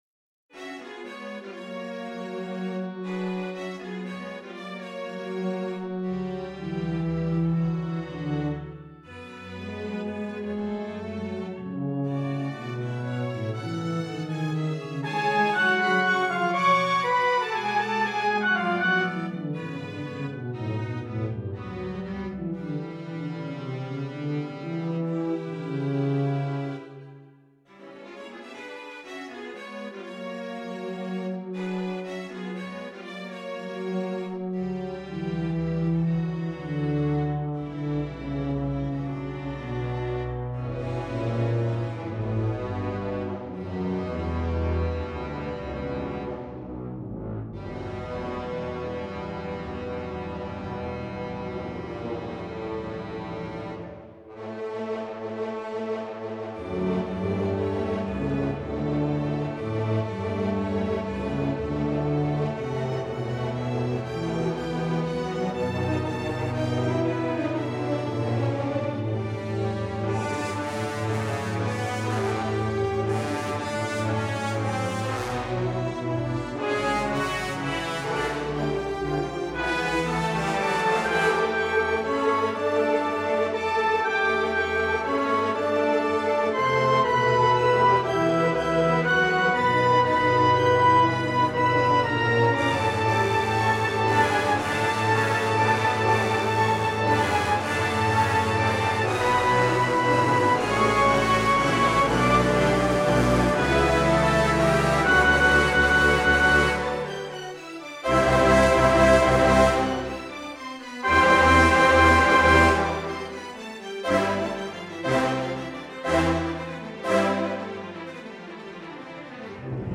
Klangbeispiele: Einmal Klassik und einmal Jazz.